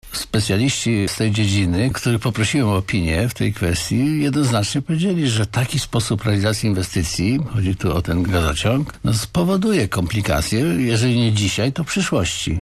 To szokująca informacja - tak na naszej antenie skomentował doniesienia gazety Nasz Dziennik Janusz Żmurkiewicz, prezydent Świnoujścia.